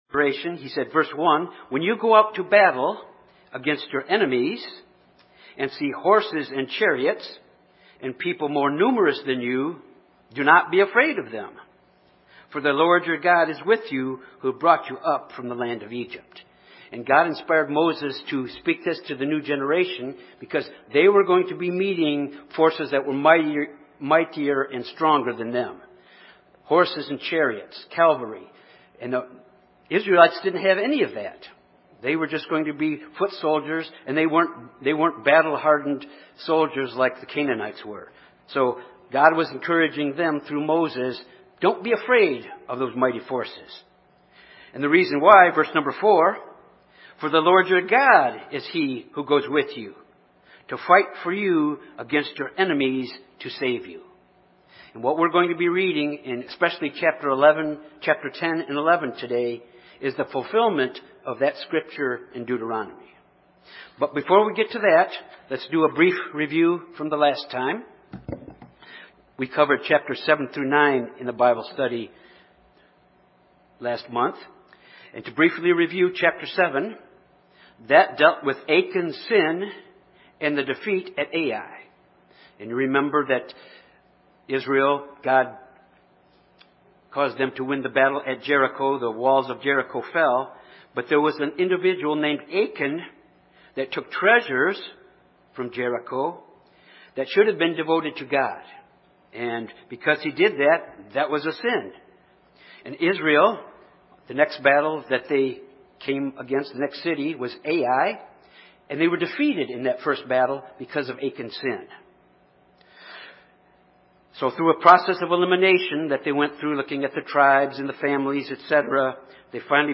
This Bible study covers Joshua, chapters 10-12. Chapter 10 deals with the conquest of Jerusalem and the southern coalition against Israel. Chapter 11 details a coalition of northern Canaanite tribes uniting against Israel. Chapter 12 is a summary of all the battles where Israel acquired the land in Canaan.